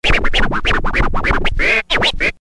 Звуки чечетки
На этой странице собраны яркие звуки чечетки в разных темпах и вариациях.